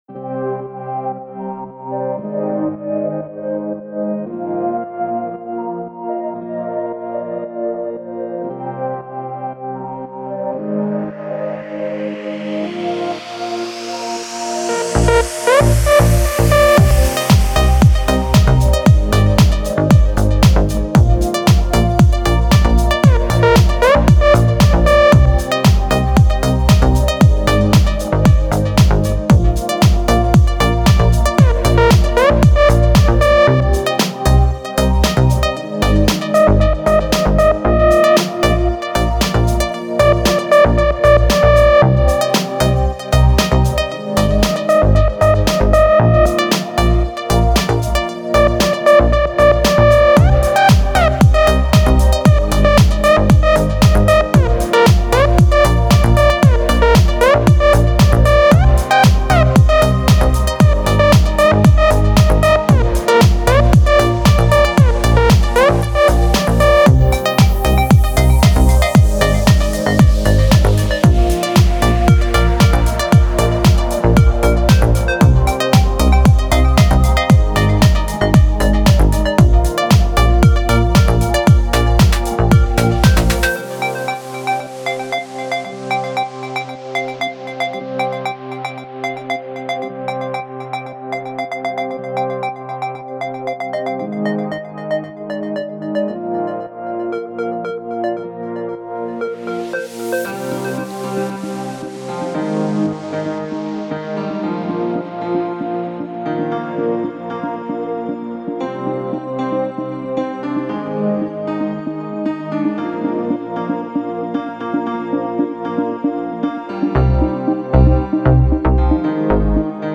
красивая музыка без слов